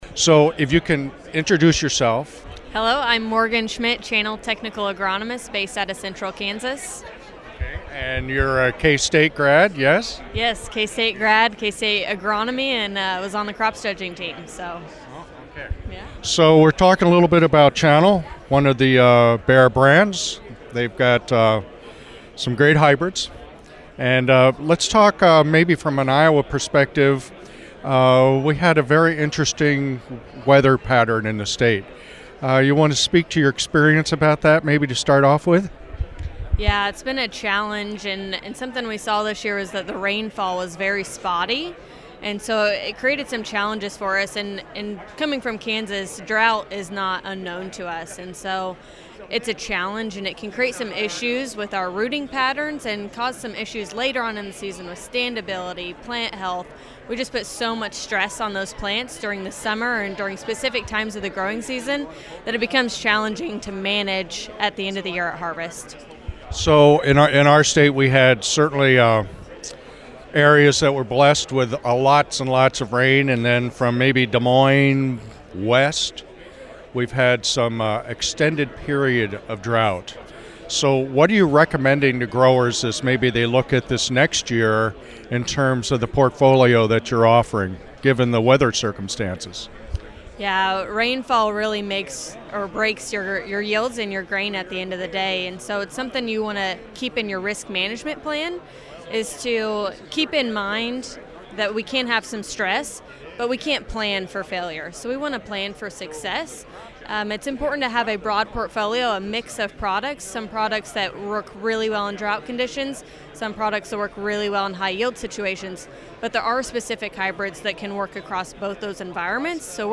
Audio: Full Interview